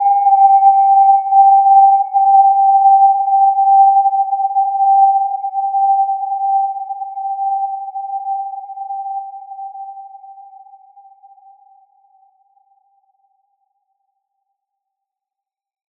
Gentle-Metallic-3-G5-p.wav